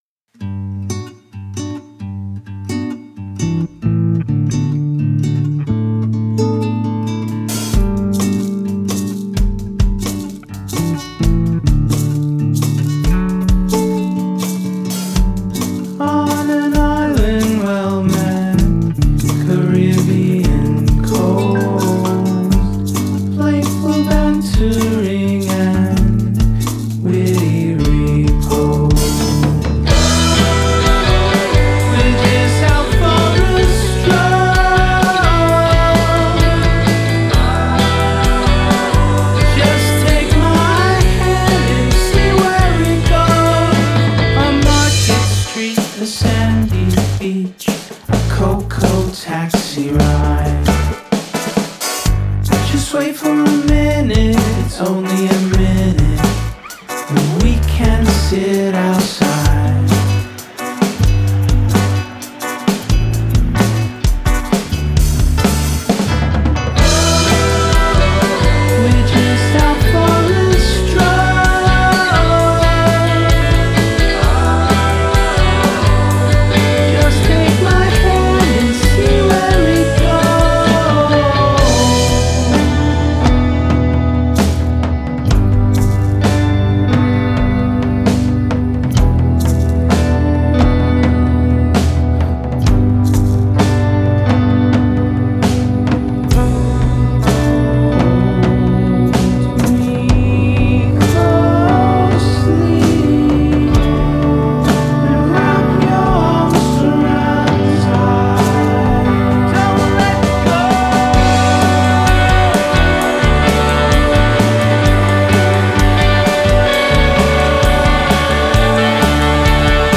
Genre: indierock.